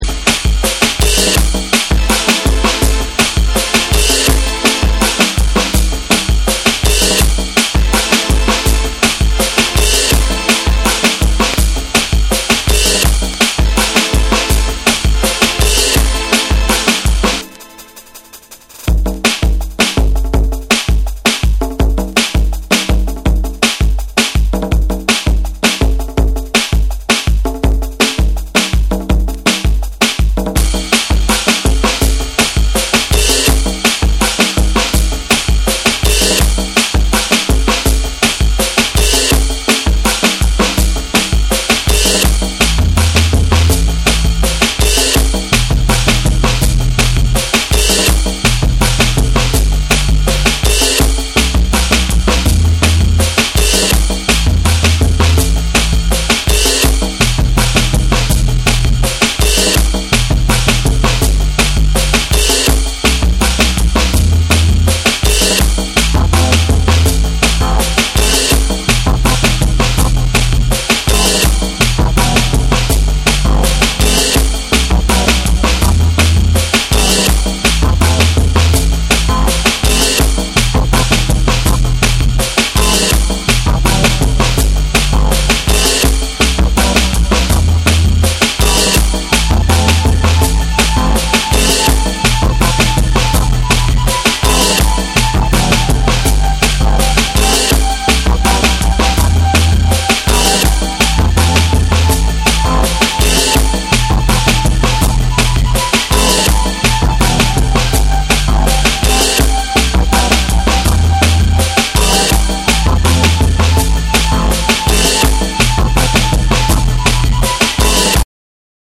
躍動する低音ブリブリのベースに、パーカッシヴなビートとジャジーなドラムが交わる
BREAKBEATS